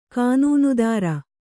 ♪ kānūnudāra